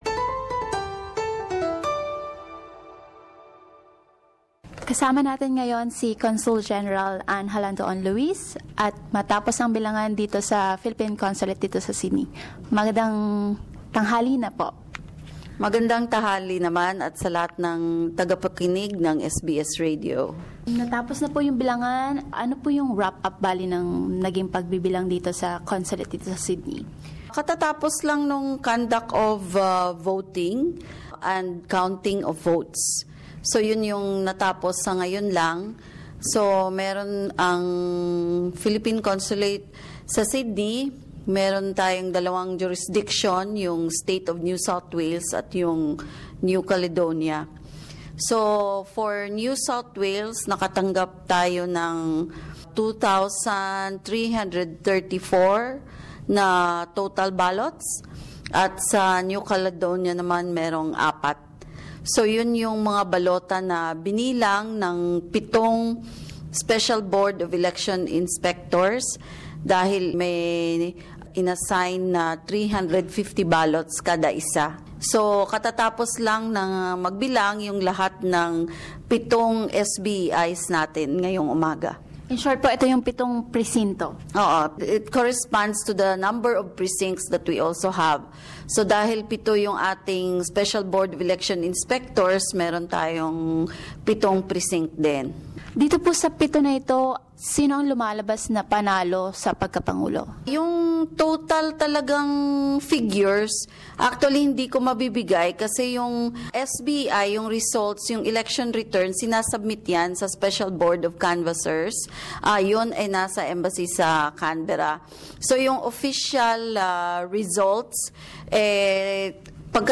Consul-General Anne Jalandoon-Louis gives a wrap-up of voting and results of election in New South Wales.